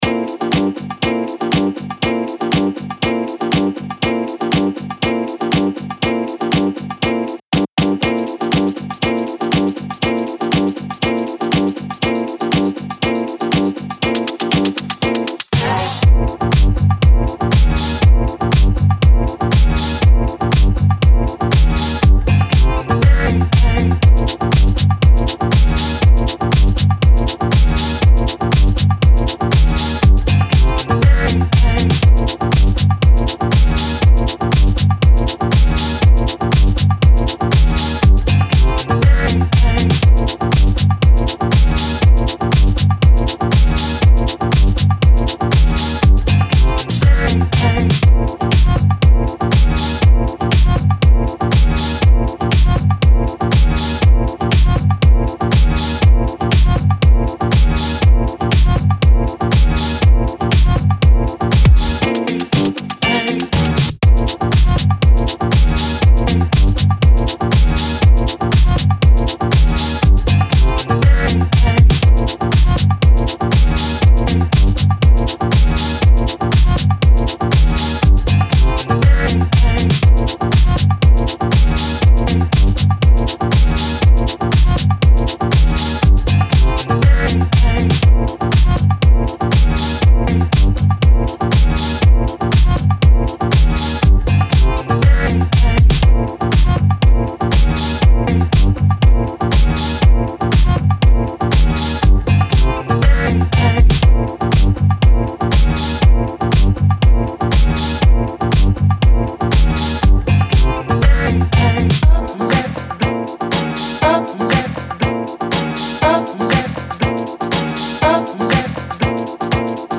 Dance Music Album cover